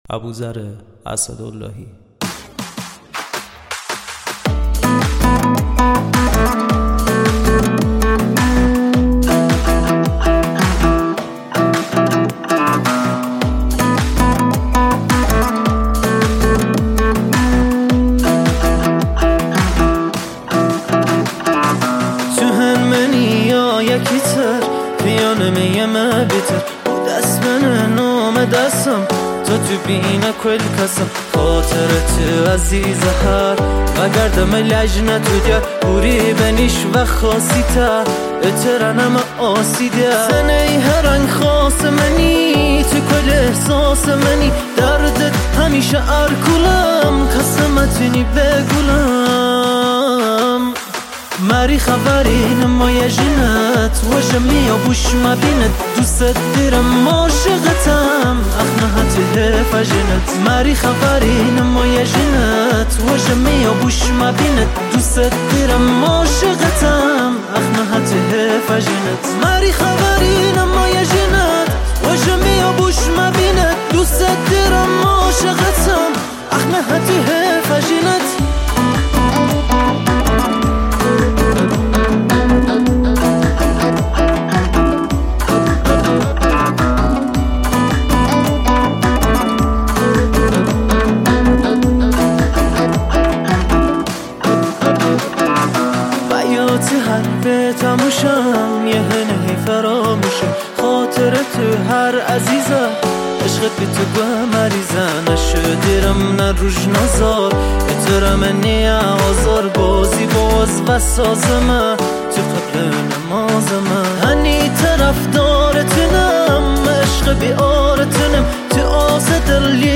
دانلود آهنگ لری
Luri music
گیتار